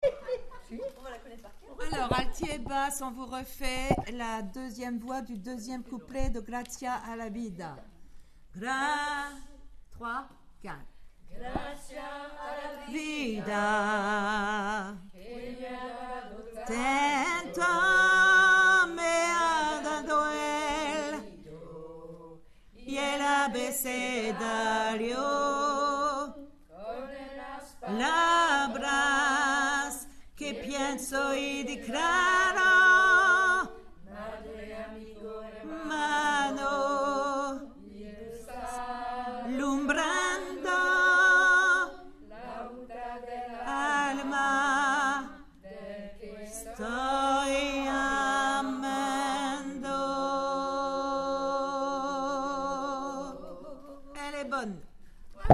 Gracias Alti Basse 3